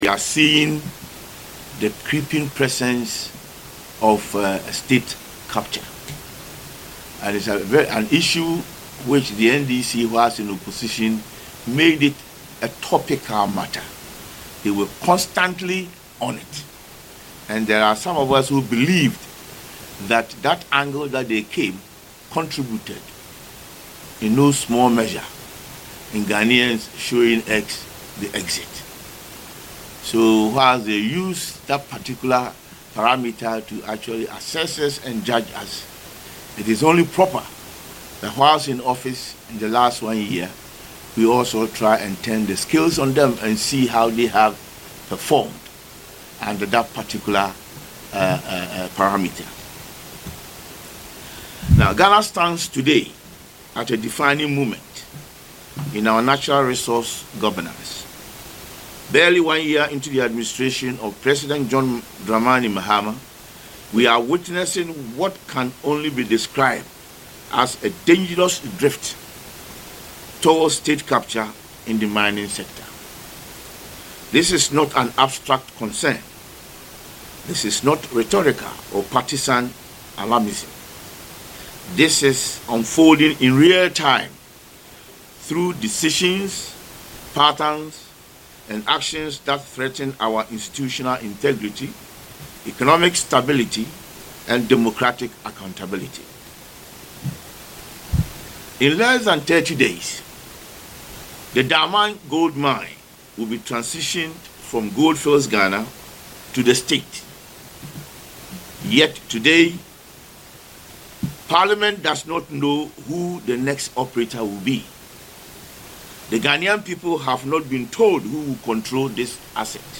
Addressing journalists in Parliament, the minority spokesperson on lands and natural resources, Kwaku Ampratwum-Sarpong, alleged that since the president’s return to office, companies linked to Ibrahim Mahama have gained unprecedented and unfair advantages.